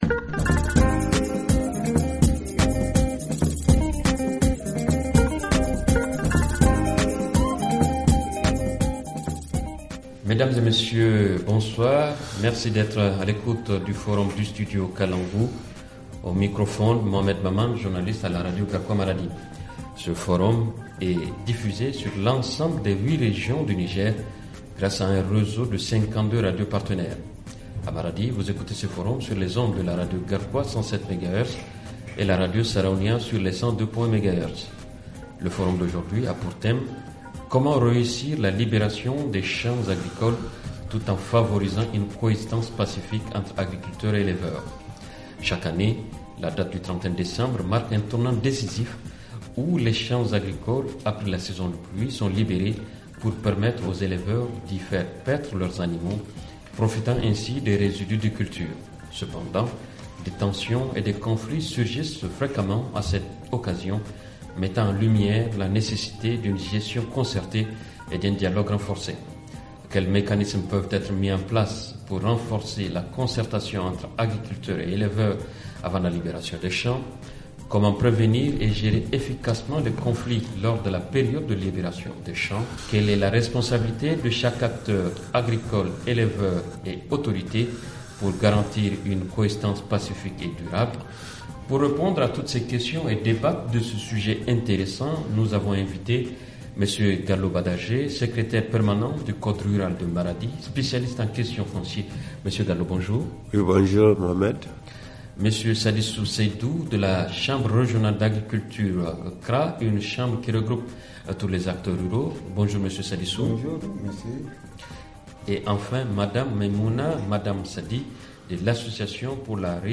FR Le forum en français Télécharger le forum ici.